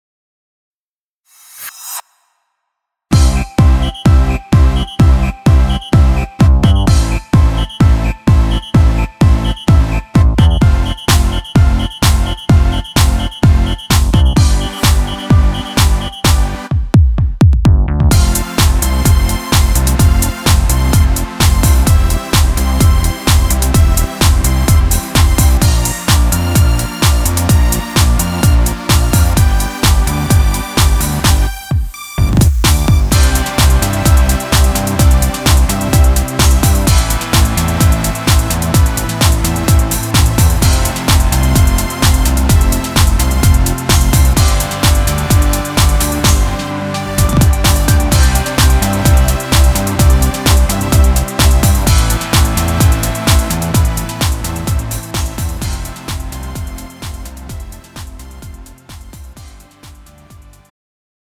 음정 원키 3:15
장르 가요 구분 Pro MR